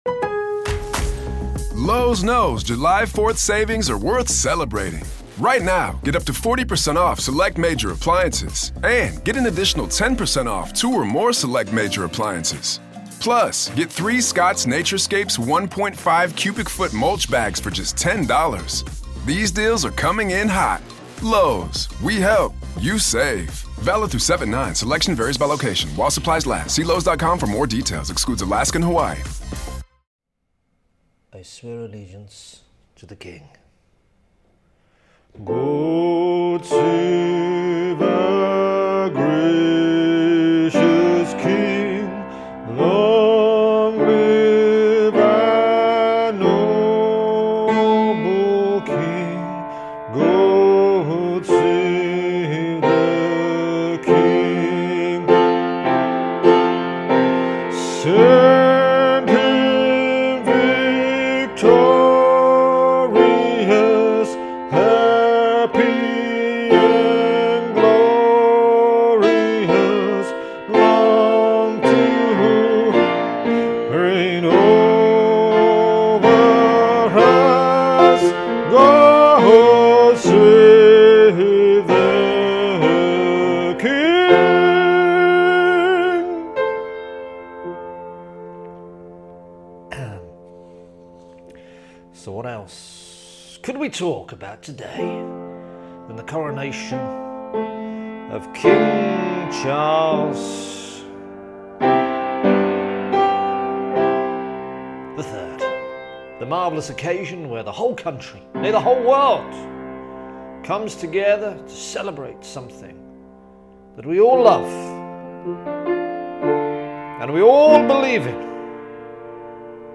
Season 4 is a limited season of shorter bits mainly without piano